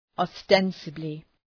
Leave a reply ostensibly Dëgjoni shqiptimin https
{ɒ’stensəblı}